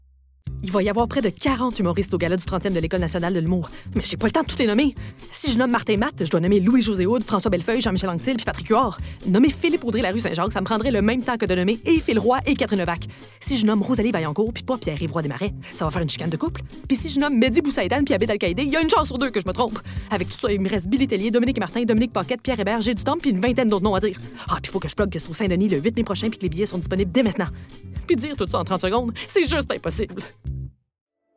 Timbre Grave
Gala École nationale de l’humour - Débit très rapide - Nerveuse - Québécois naturel /
Annonceuse - Fictif 2022 00:31 788 Ko